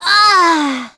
Gremory-Vox_Damage_04.wav